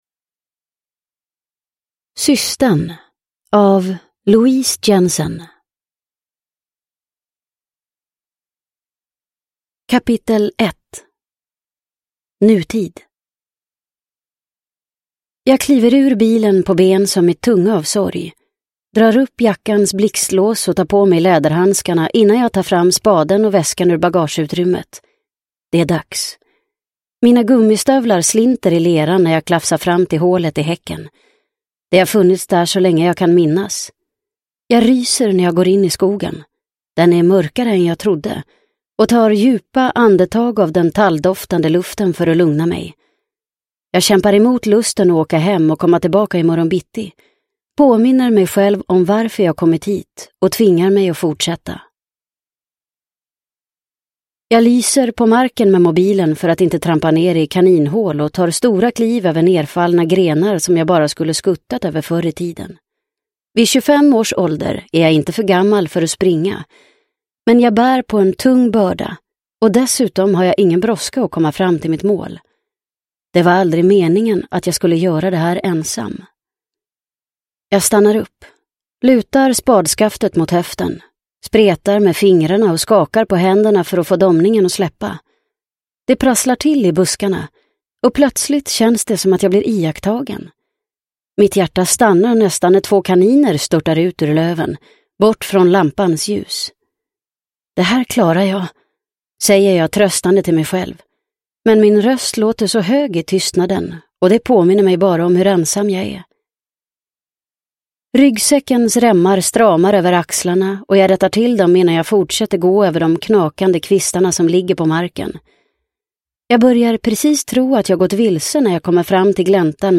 Systern – Ljudbok – Laddas ner